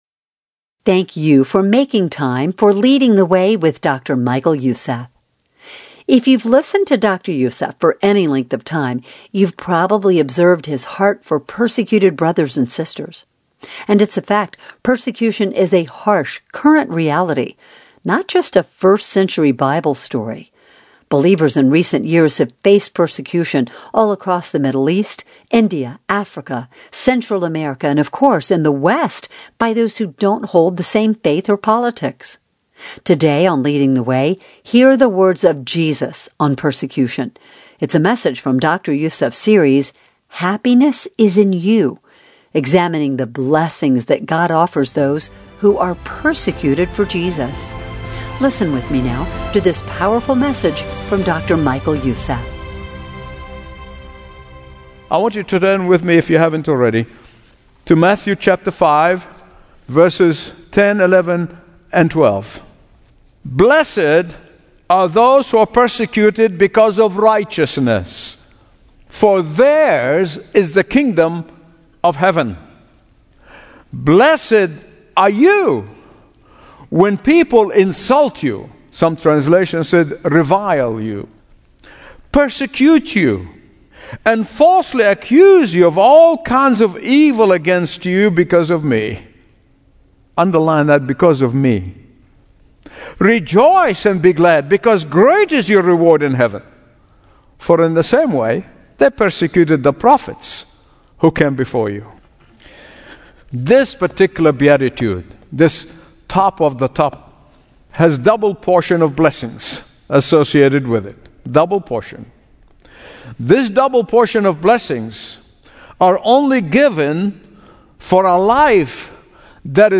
Daily Bible Teachings